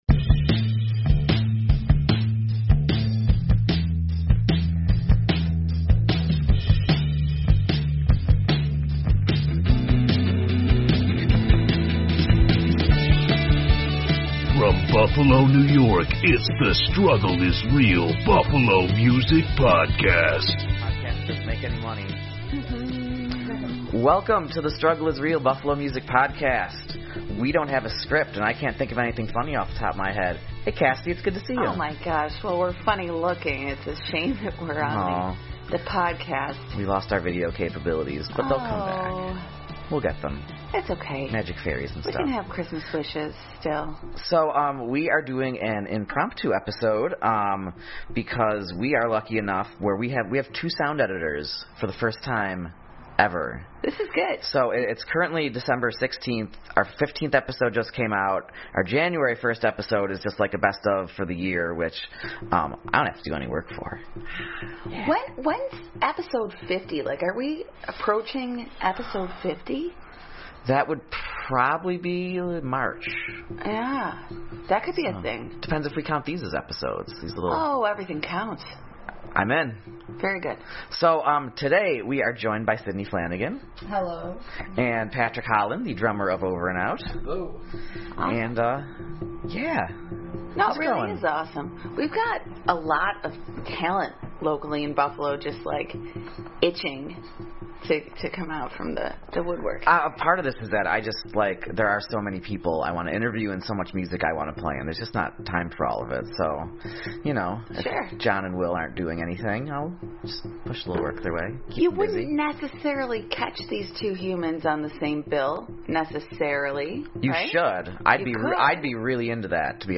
The Struggle is Real is ending 2020 with a BANG! We have a BONUS, no-script episode